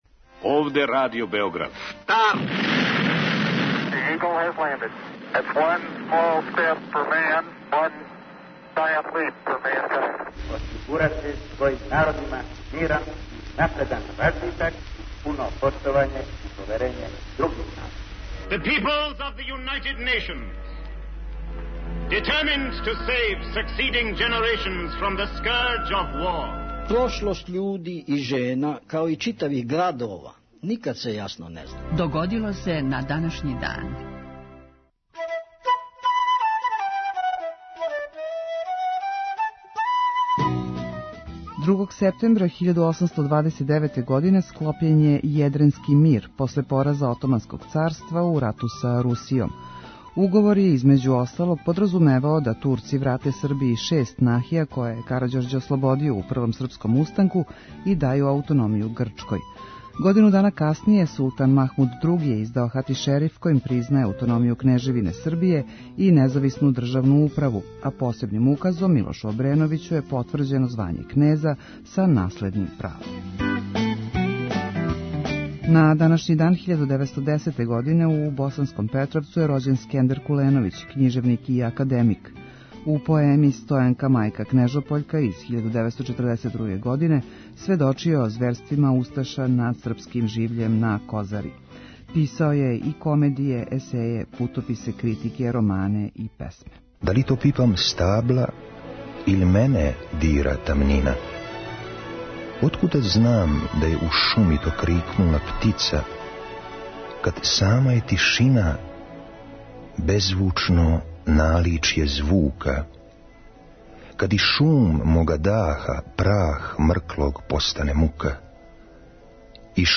У петотоминутном прегледу, враћамо се у прошлост и слушамо гласове људи из других епоха.